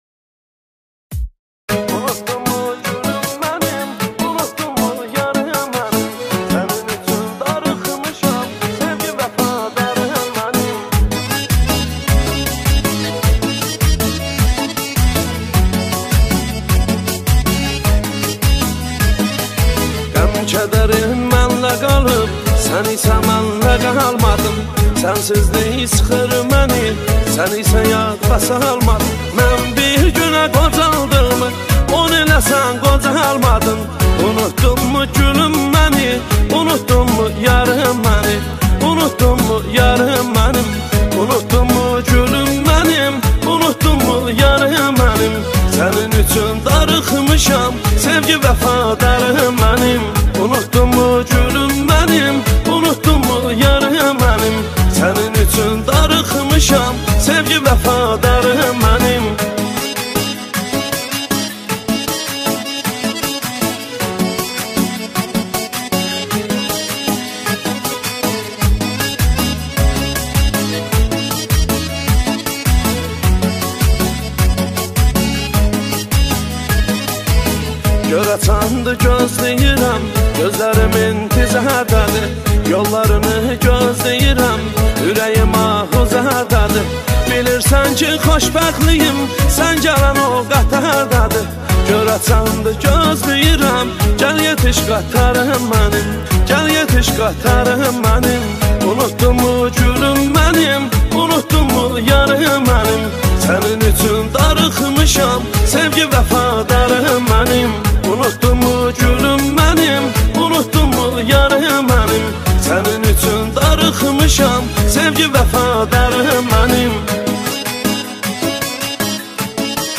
Жанр: Турецкая музыка